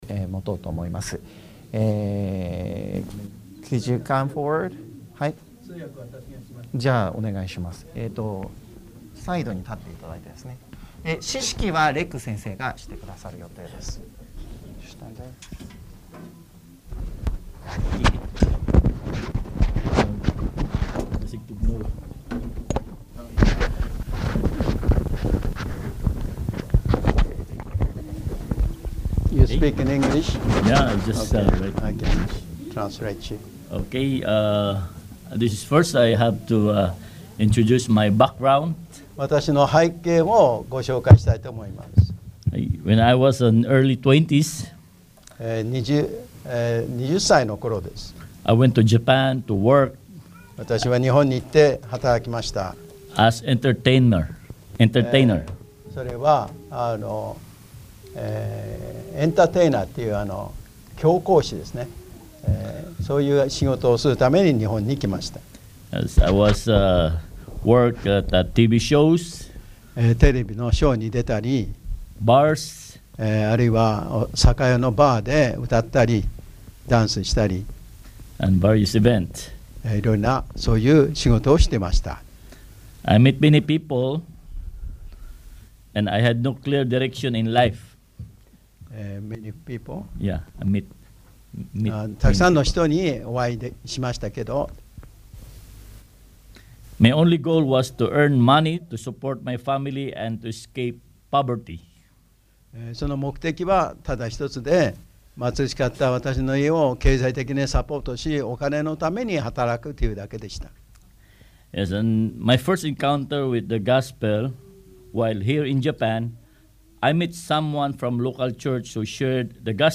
希望の灯は、我孫子バプテスト教会が放送している福音ラジオ番組です。